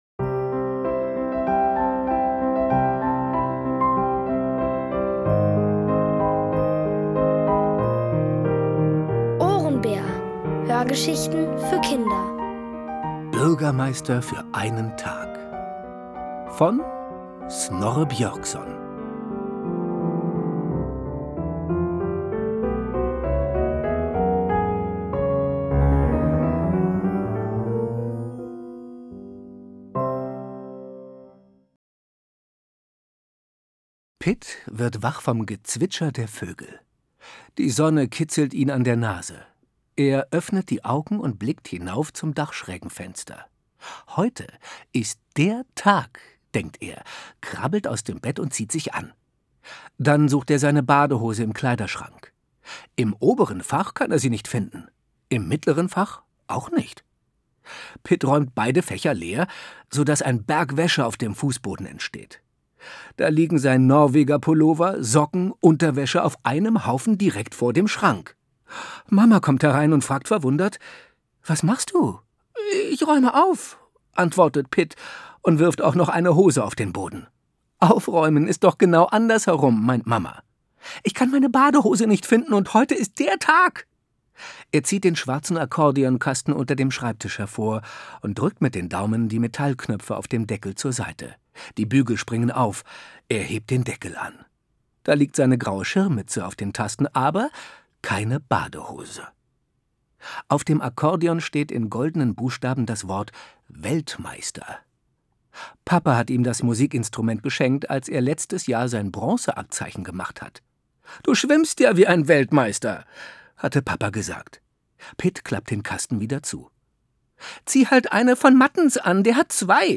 Bürgermeister für einen Tag | Die komplette Hörgeschichte!
Von Autoren extra für die Reihe geschrieben und von bekannten Schauspielern gelesen.